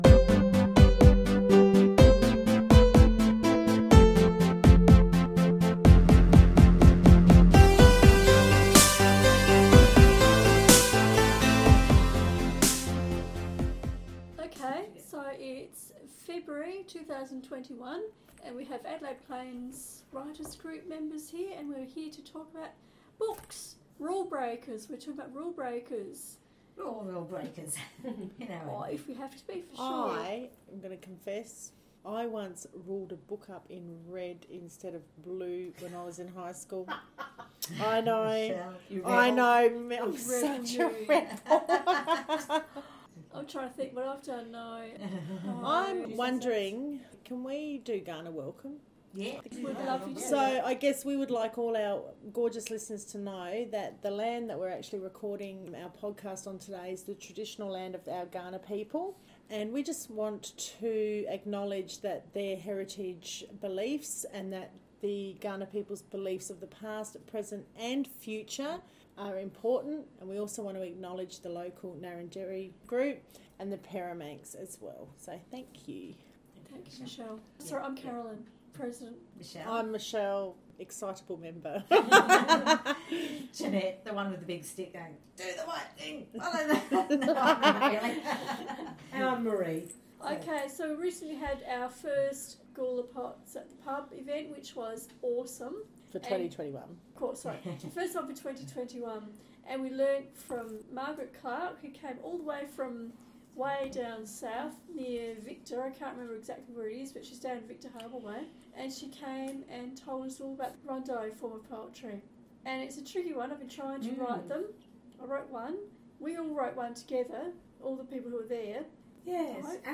Adelaide Plains Chapter and Verse monthly podcast recorded Wednesday 3rd February, Gawler South.
Opening music